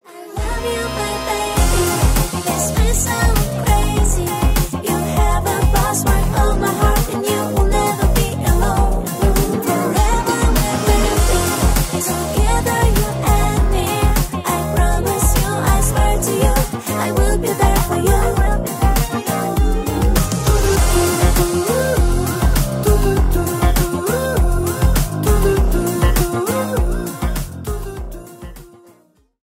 Узбекские